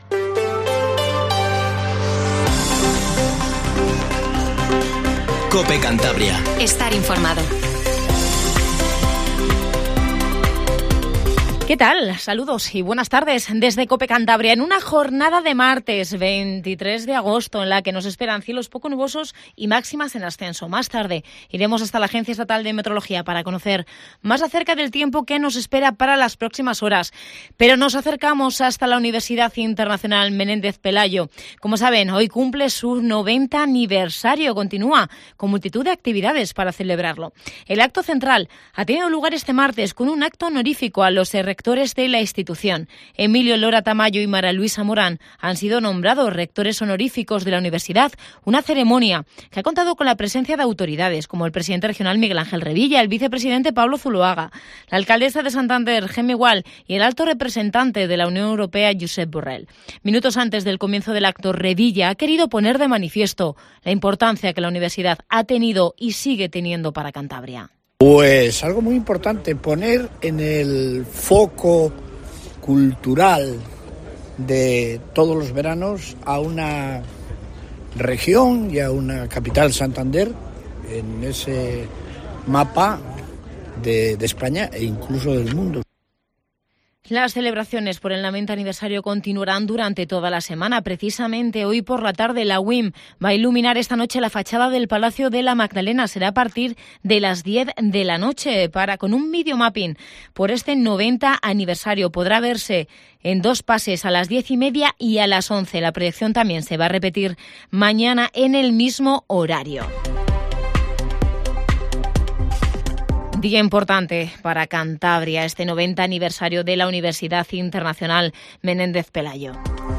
Informativo Matinal Cope